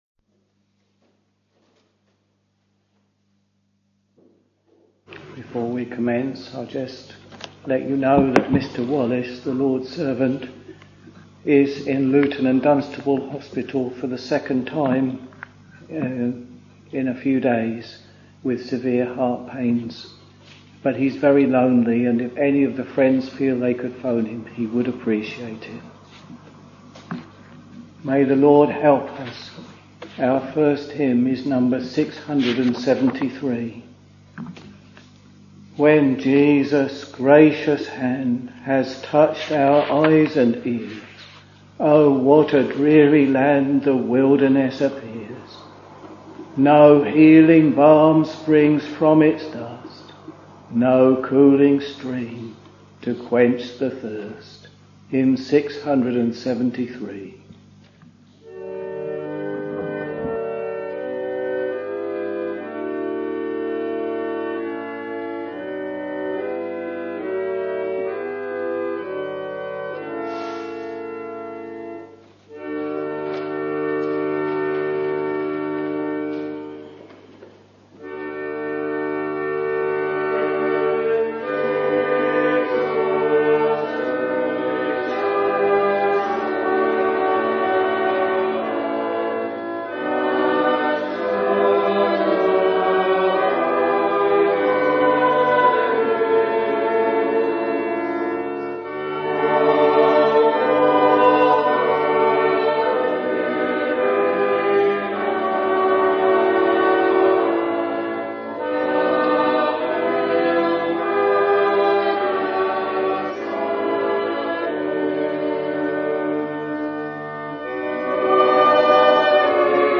Week Evening Service Preacher